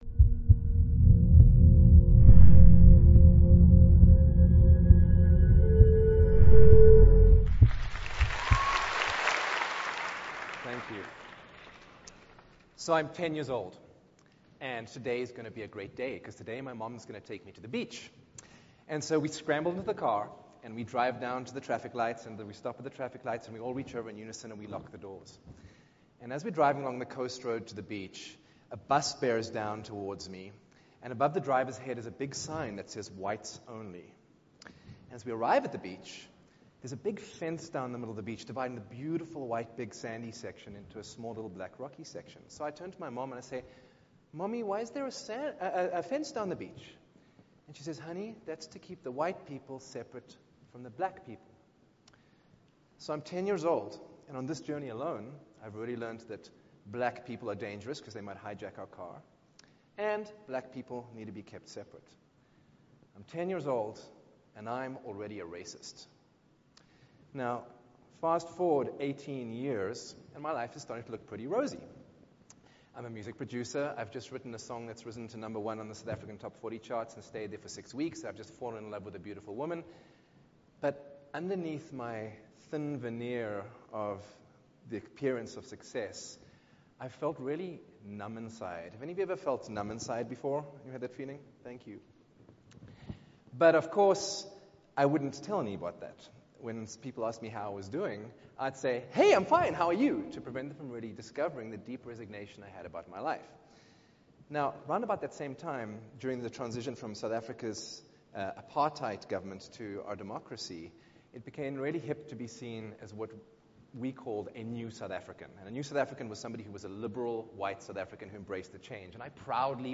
TEDxSinCity